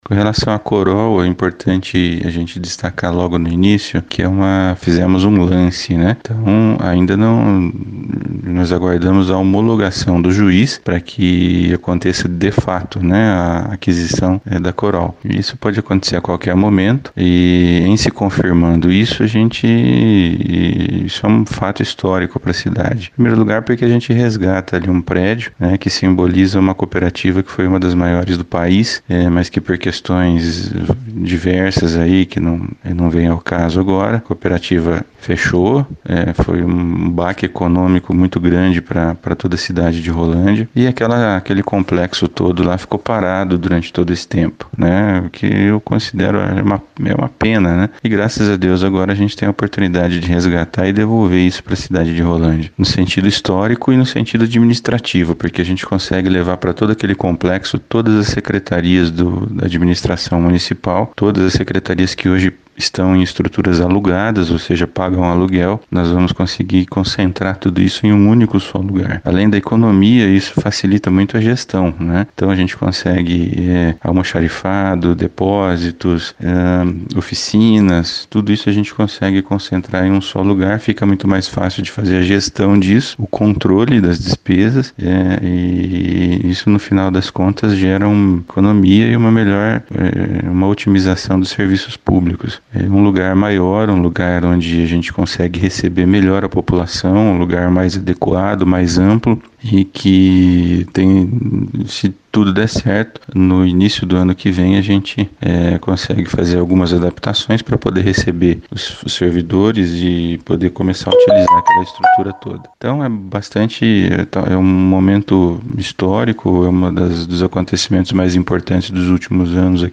SONORA-PREFEITO-LUIZ-FRANCISCONI-COMPRA-PRÉDIO-COROL.mp3